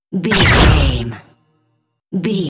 Dramatic stab laser shot deep energy
Sound Effects
heavy
intense
dark
aggressive